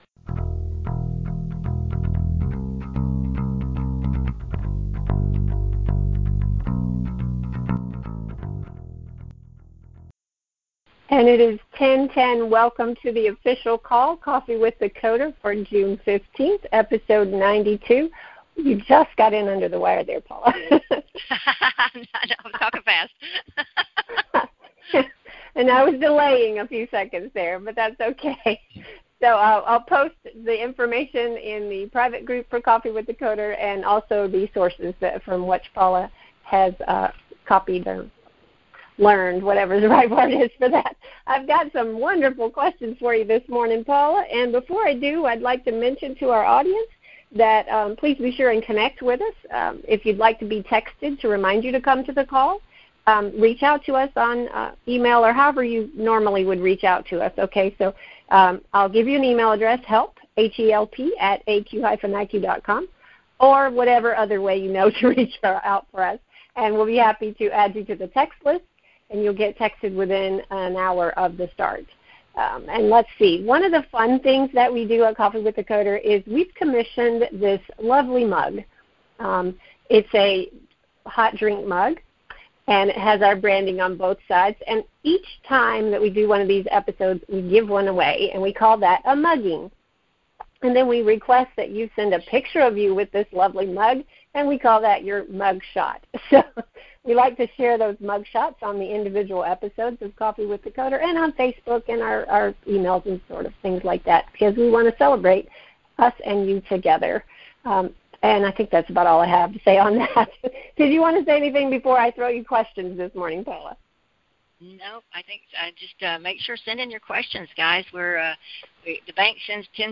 Welcome to Coffee with the Coder®, our conversation